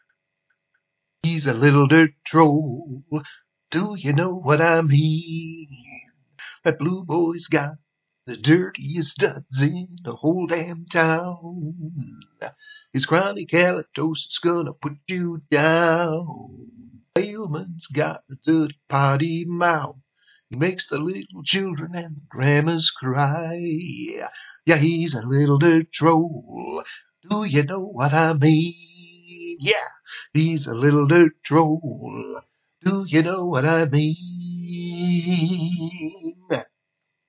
and he sang few lines a Capella